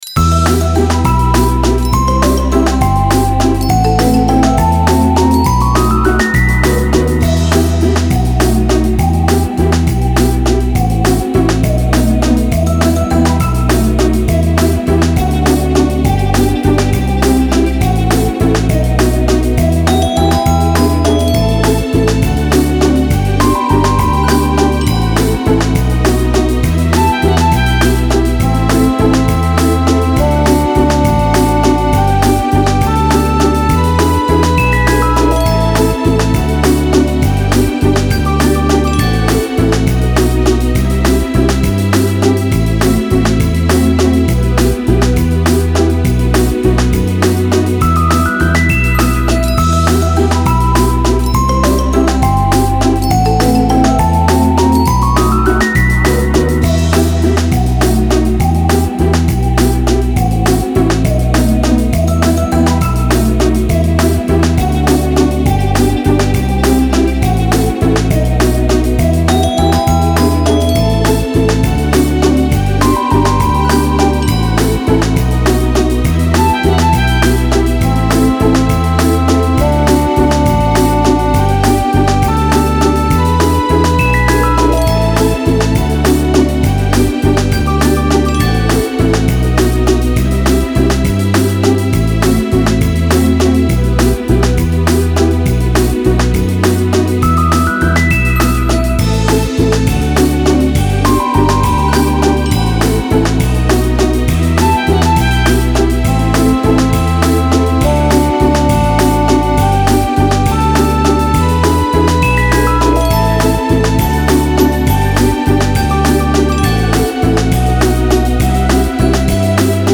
Музыка для выходных